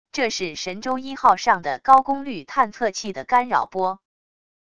这是神州一号上的高功率探测器的干扰波wav音频